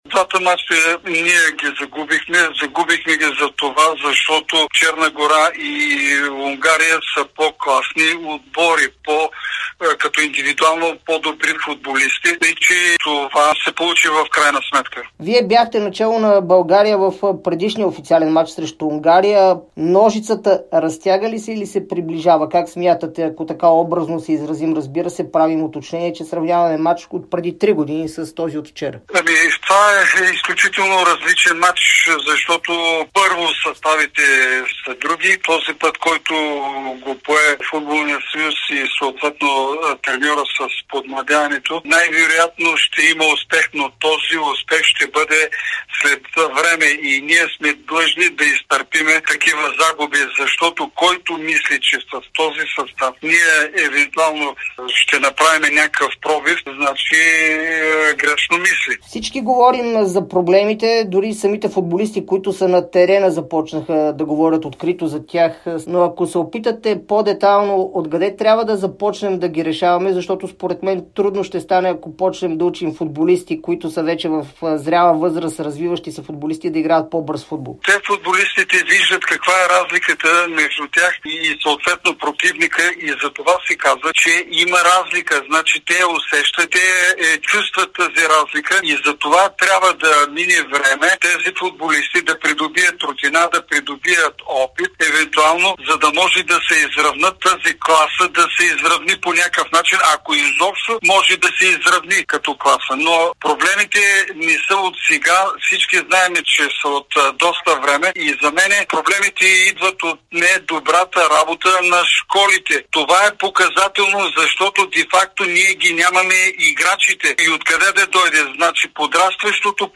Бившият селекционер на България Георги Дерменджиев говори пред Дарик и dsport след първите два мача на "лъвовете" от евроквалификациите, в които момчетата на Младен Кръстаич записаха две загуби - 0:1 от Черна гора и 0:3 от Унгария.